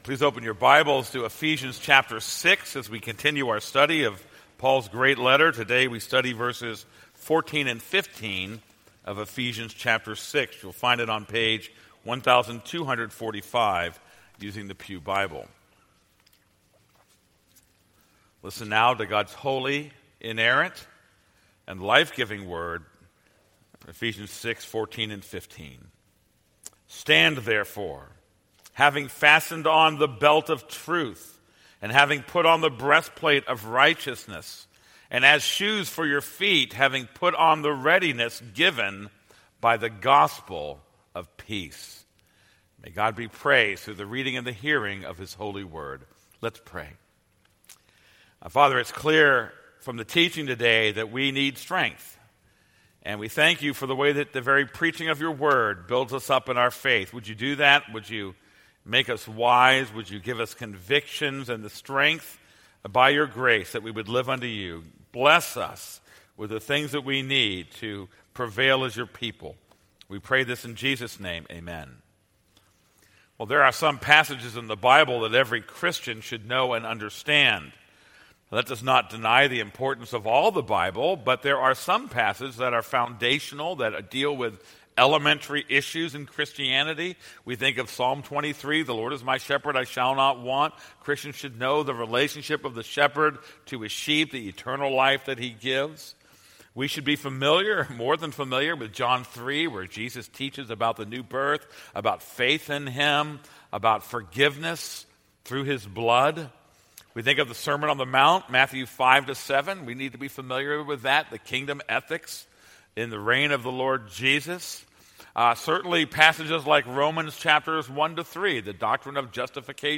This is a sermon on Ephesians 6:14-15.